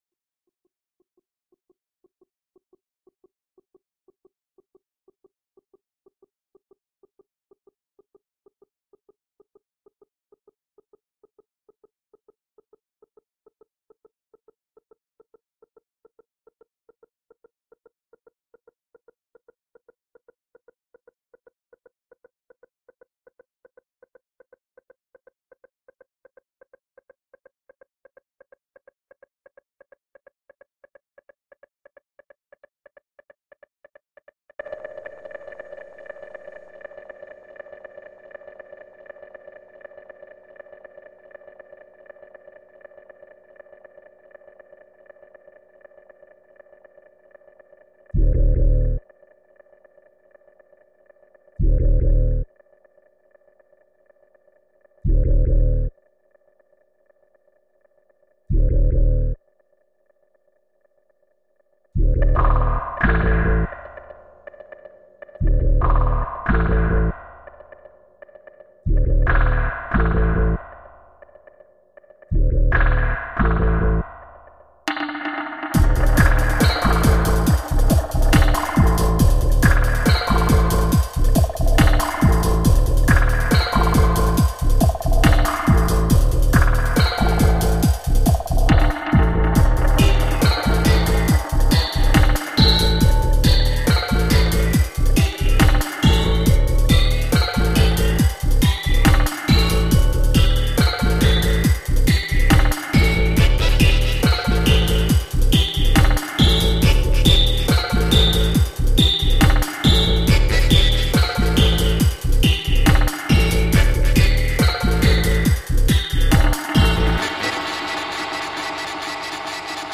a wormhole of heavy bass......